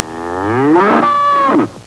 snd_18941_Cow.wav